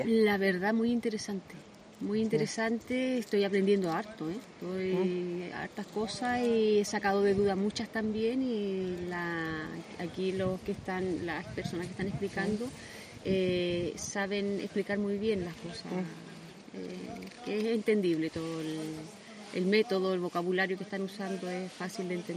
Dia-de-Campo-agricultora.mp3